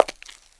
PAVEMENT 1.WAV